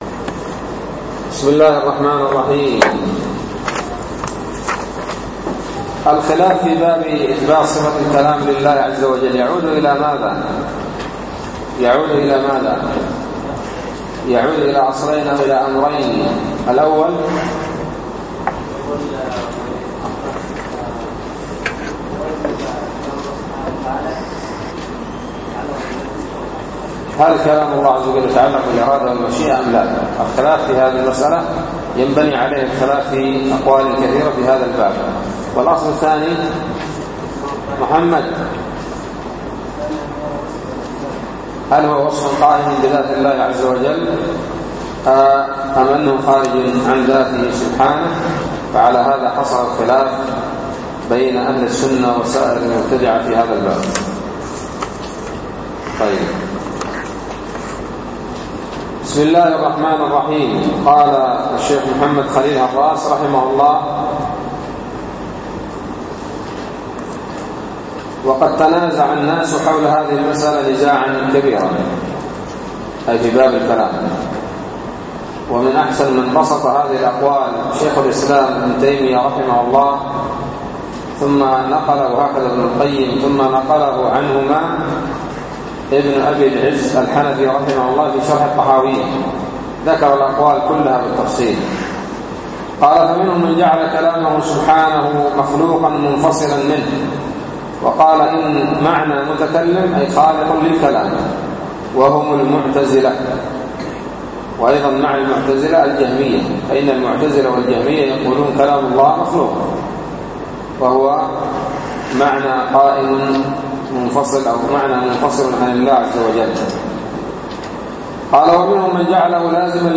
الدرس التاسع والسبعون من شرح العقيدة الواسطية